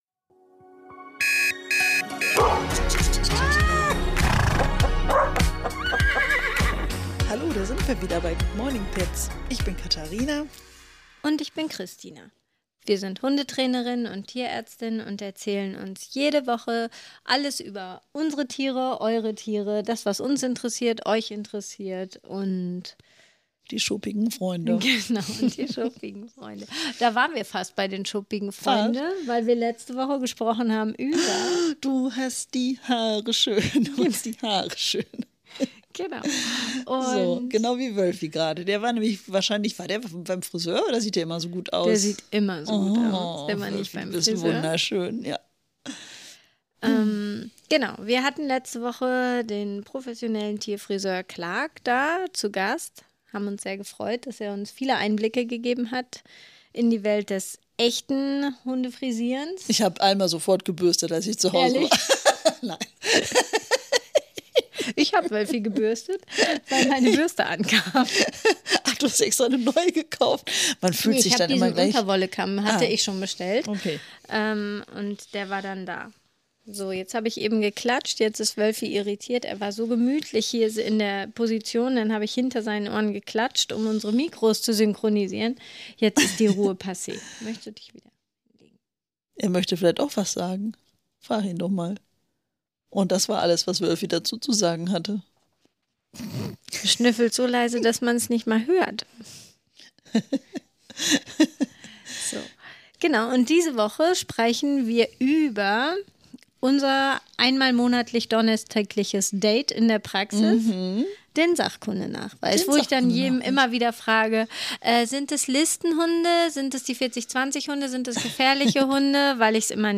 Kurze Exkurse zu Alltagsproblemen – vom Ziehen an der Leine bis zur Ressourcenverteidigung – runden das Gespräch ab.